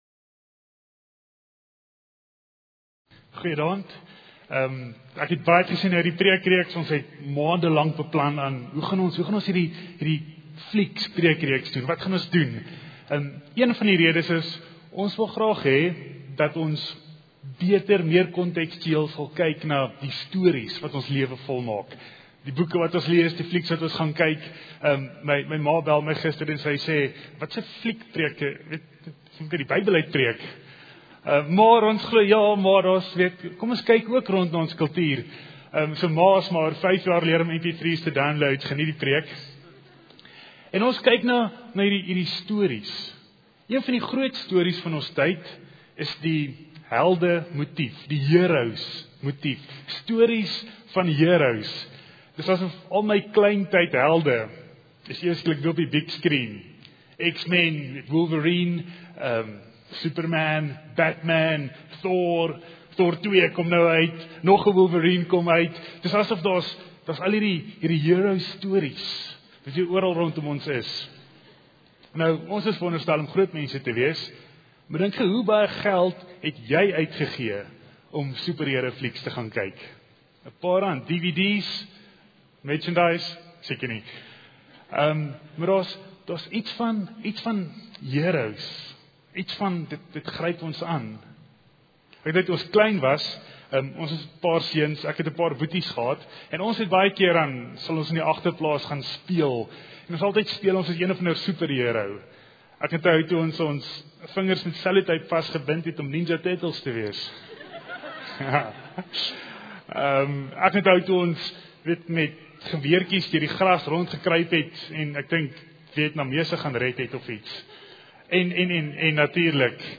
Prediker